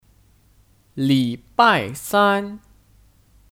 礼拜三 (Lǐbài sān 礼拜三)